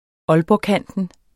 Udtale [ ˈʌlbɒˌkanˀdən ] Betydninger området omkring Aalborg